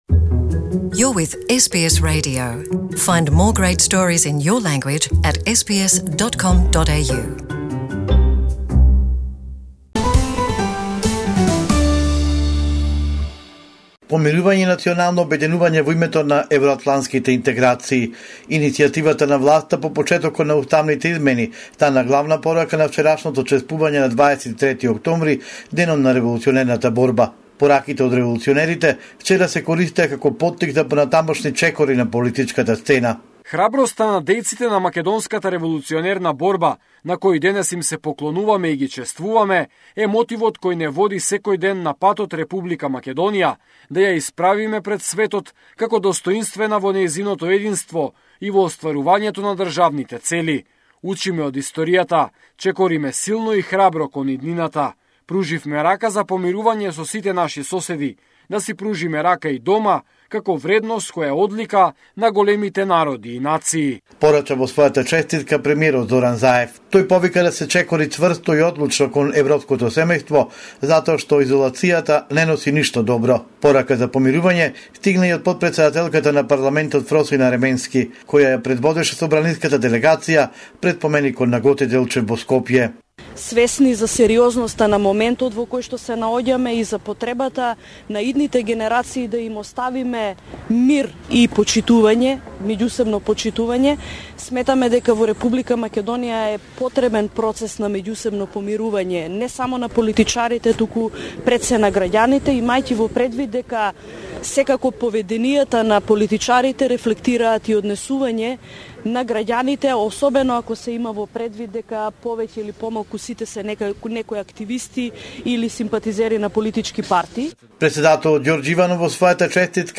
President Gjorge Ivanov, Prime Minister Zoran Zaev and VMRO DPMNE President Christian Mickoski with mixed political messages on the occasion of the Day of the Macedonian Revolutionary Struggle - 23 October. Report